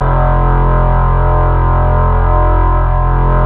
Index of /90_sSampleCDs/Roland LCDP02 Guitar and Bass/BS _Synth Bass 1/BS _Wave Bass
BS  FUZZY 05.wav